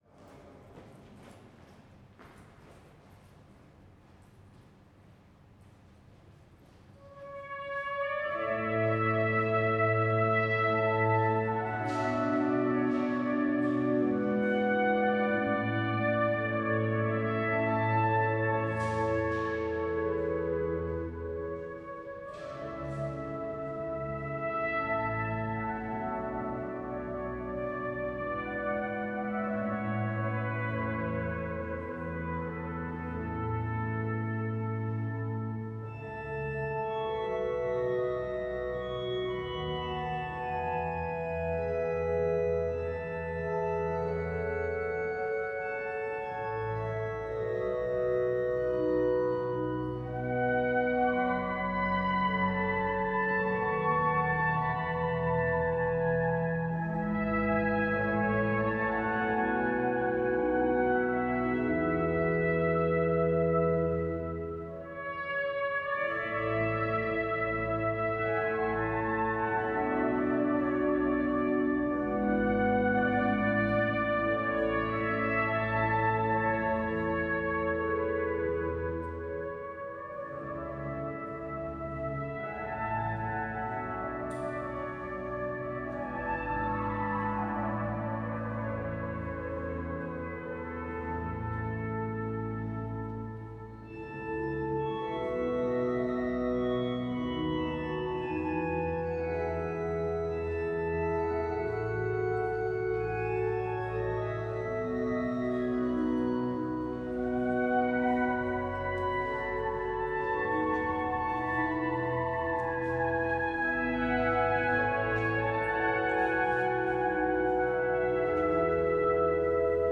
Metz, cathédrale - 4017 / CCM8 / SD788
orgue.mp3